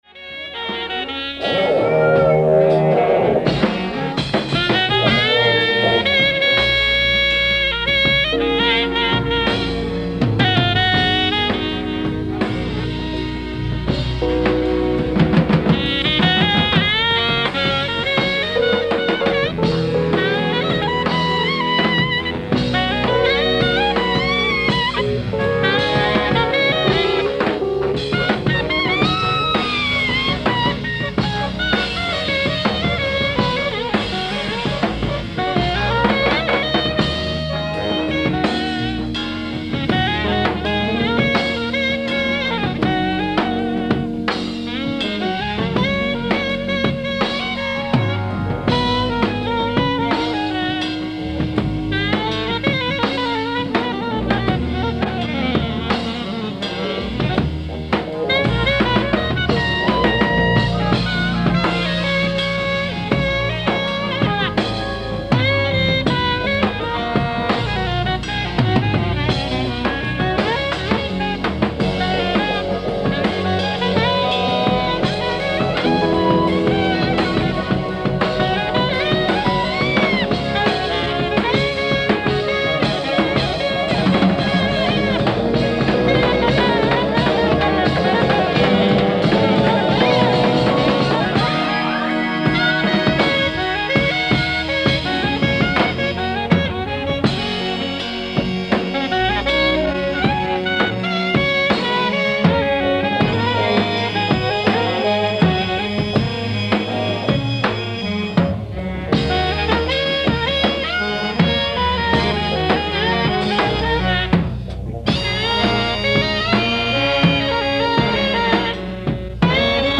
ライブ・アット・ラブェンナ、イタリア
※試聴用に実際より音質を落としています。